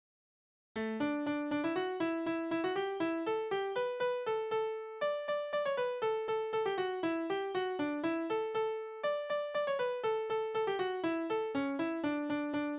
Tonart: D-Dur
Taktart: 4/8
Tonumfang: Oktave, Quarte
Besetzung: vokal